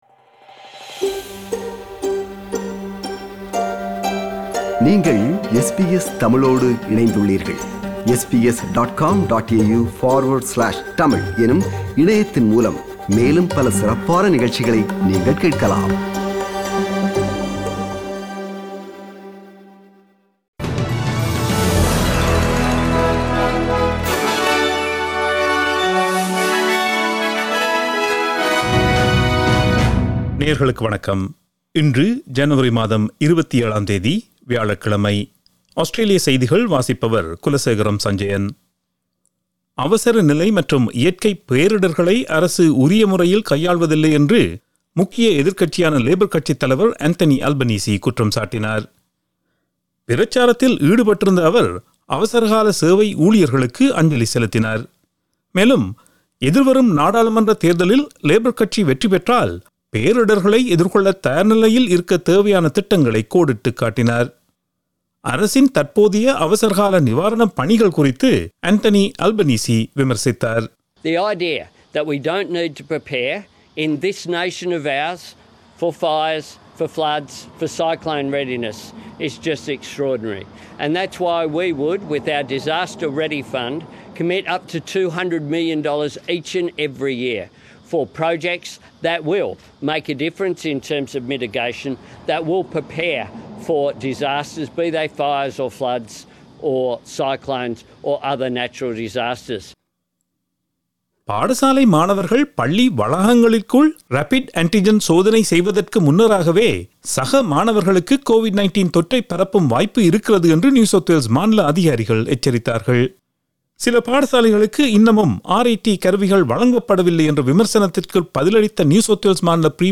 Australian news bulletin for Thursday 27 January 2022.